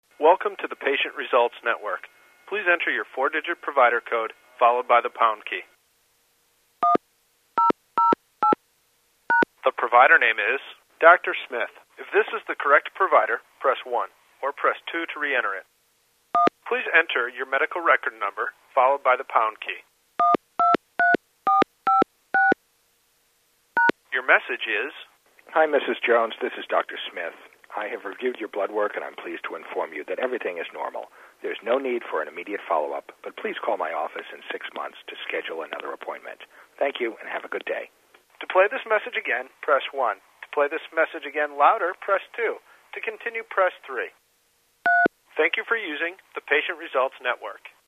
phone1.mp3